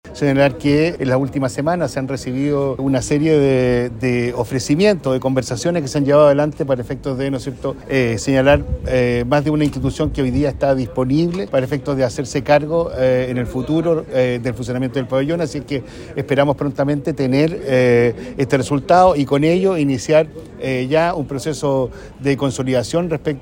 En ese sentido, el delegado Presidencial de La Araucanía, Eduardo Abdala, indicó que ya existen conversaciones en relación con este tema.